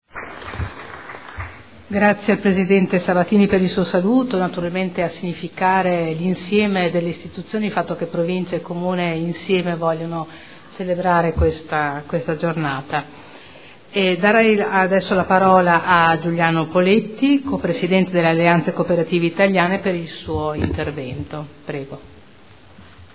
Seduta del 22/11/2012. Intervento su celebrazione dell’Anno internazionale delle cooperative indetto dall’ONU per il 2012